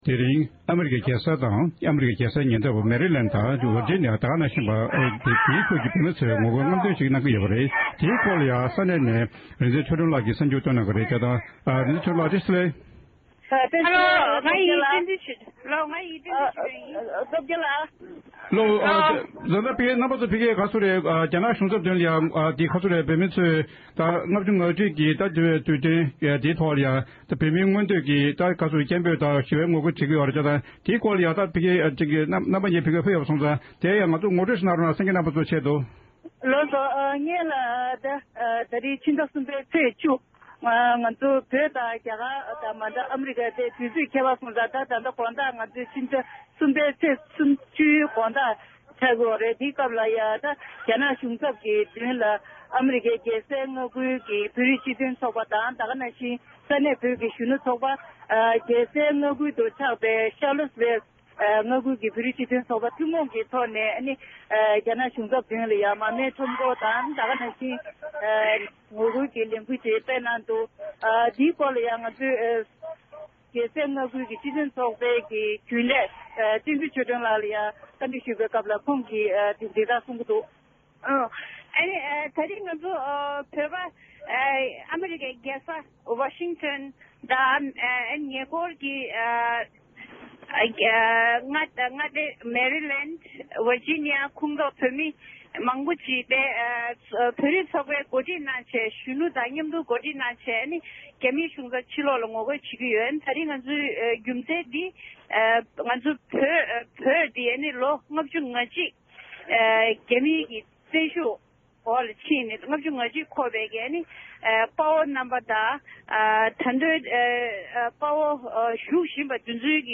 ༄༅༎ཕྱི་ཟླ་གསུམ་པའི་ཚེས་བཅུ་རེས་གཟའ་ལྷག་པའི་ཉིན་བོད་མིས་རྒྱ་ནག་གི་བཙན་འཛུལ་ལ་སྒེར་ལངས་བྱས་ནས་མི་ལོ་ལྔ་བཅུ་ང་གཅིག་འཁོར་བའི་ཉིན་མོར་ཨ་རིའི་རྒྱལ་ས་ཝ་ཤིང་ཀྲིན་དང༌།རྒྱ་གར་གྱི་རྒྱལ་ས་ལྡི་ལི།བལ་ཡུལ་གྱི་རྒྱལ་ས་ཀཋ་མཎ་ཌུ།རྒྱ་གར་ལྷོ་ཕྱོགས།ཡོ་རོབ།ཨོས་ཊོ་ལི་ཡ།ཁེ་ན་ཌ་སོགས་འཛམ་གླིང་ནང་བོད་མི་གནས་སྡོད་གང་སར་འདས་པའི་དཔའ་བོ་རྣམས་ལ་རྗེས་དྲན་གྱི་མར་མེ་དང༌།རྒྱ་ནག་ལ་ངོ་རྒོལ་གྱི་རྔམ་སྟོན་ཁྲོམ་སྐོར་གྱི་ལས་འགུལ་སྤེལ་ཡོད་པའི་སྐོར་གྱི་གནས་ཚུལ་ང་ཚོའི་གསར་འགོད་པས་བཏང་བའི་གནས་ཚུལ་ཁག་ཅིག་ལ་གསན་རོགས༎